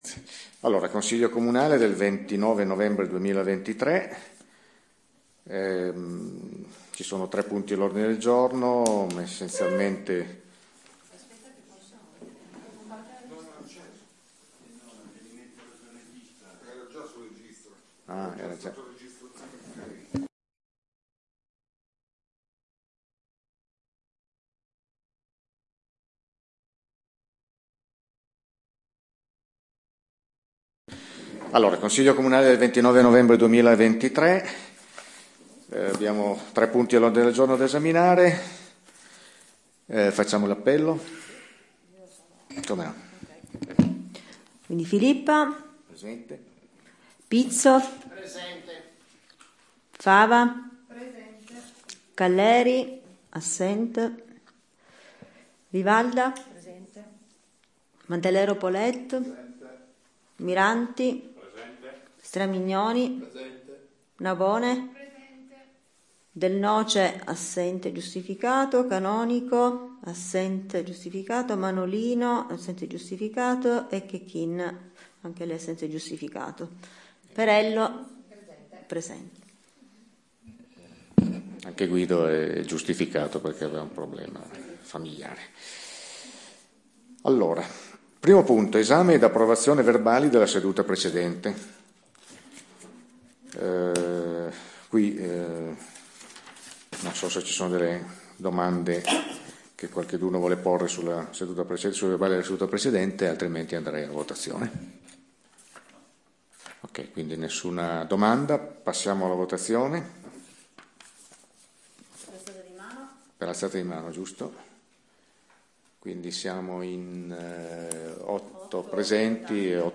Registrazione Consiglio comunale - Comune di Pecetto Torinese